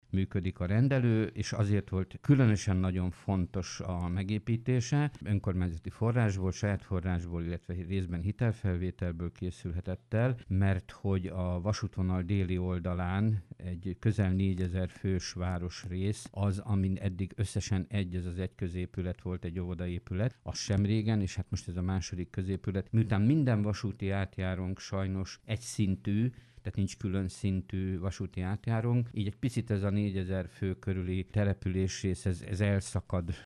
A város egészségügyi rendszere jelentőset fejlődött az elmúlt időszakban az új orvosi rendelő megépítésével. A témával kapcsolatban Fazekas László polgármestert kérdeztük.